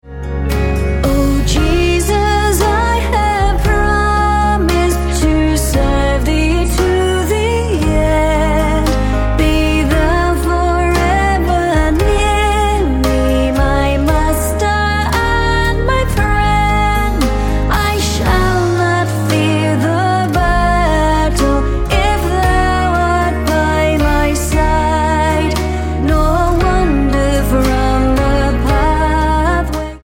Db